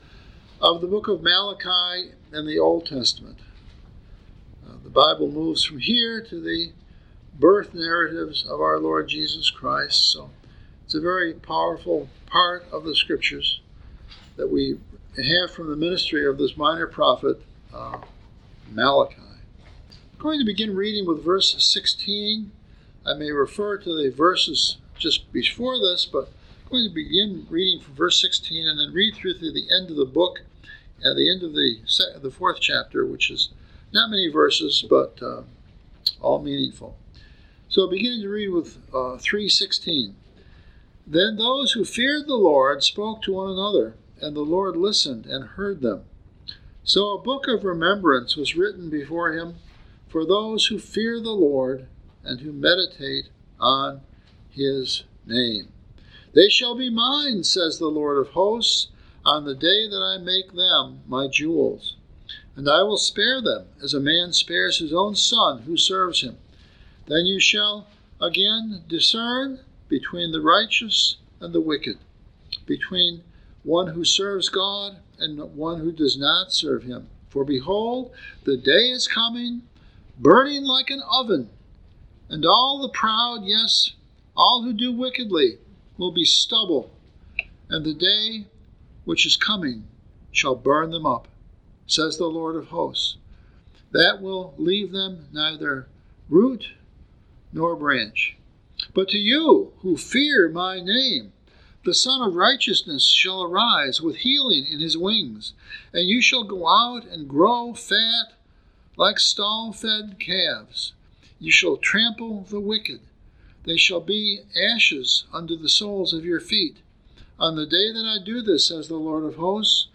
The newest sermons from Southwest Ohio Reformed Presbyterian on SermonAudio.